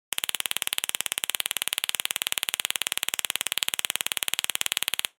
Taser Shock 4
Taser Shock 4 is a free sfx sound effect available for download in MP3 format.
yt_oJQajP6L4Sg_taser_shock_4.mp3